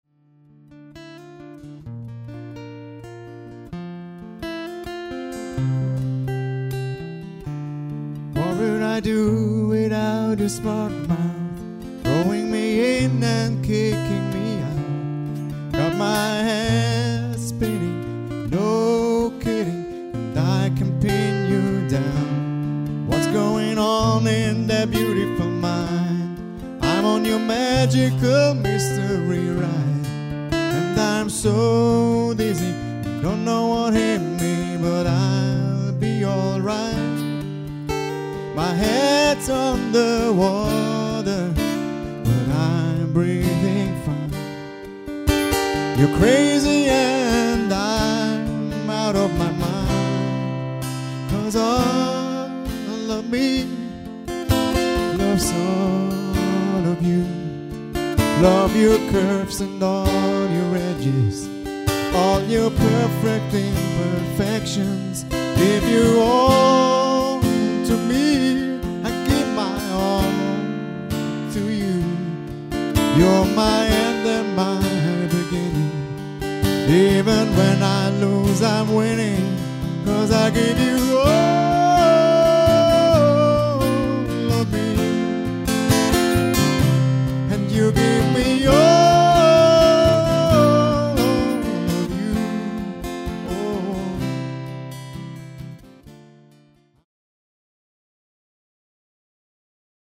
MIT VIEL SOUL BLUESIG GESUNGEN UND EINEM HAUCH VON
COUNTRY, GOSPEL, JAZZ, ROCK'N'ROLL & REGGAE UMWEHT.
Begleitet mit akustischer Gitarre.